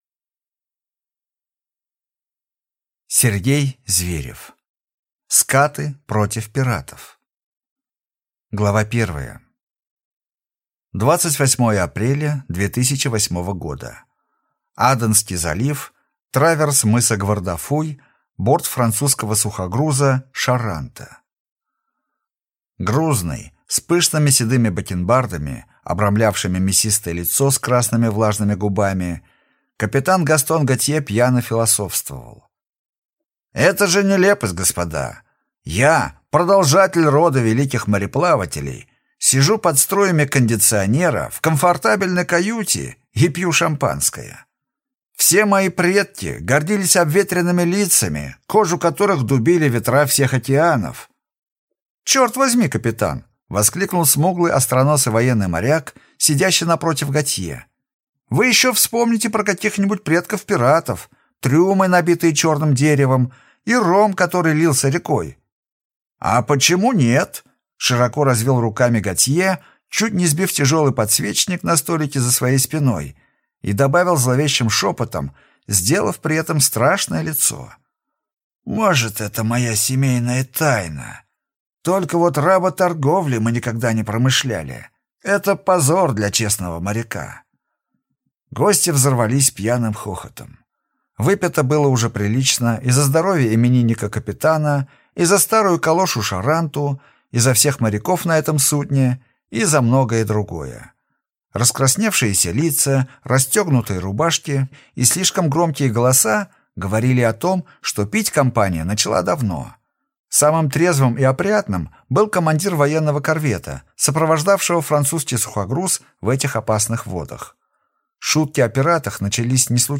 Аудиокнига «Скаты» против пиратов | Библиотека аудиокниг